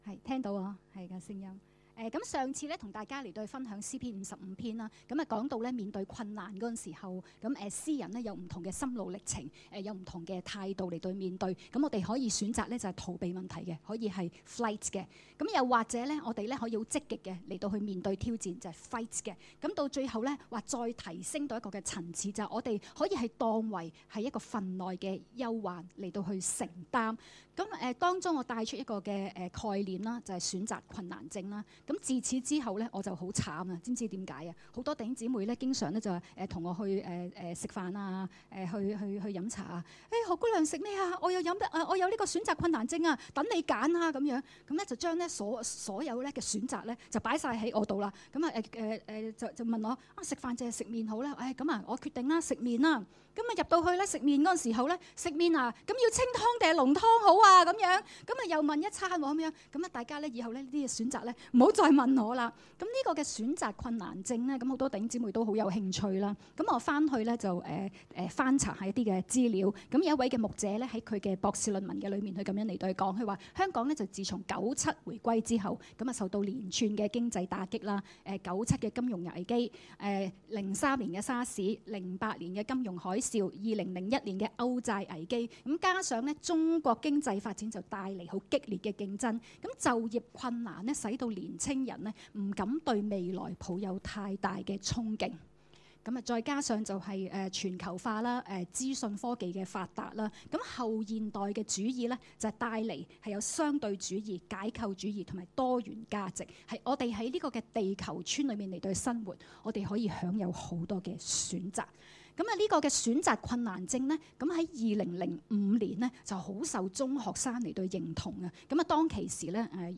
2016年7月9日及10日崇拜
講道：人生的抉擇